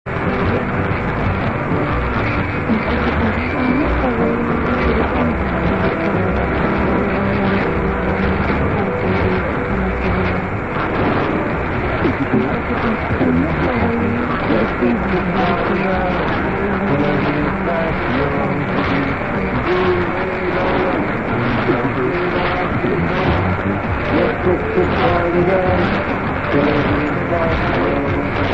STEREO - Left channel: audio from Drake R8A at Billerica, MA, USA; Right channel: audio from Ilfracombe, UK DXTuner receiver via web. Delay of web about 9 sec. relative to air.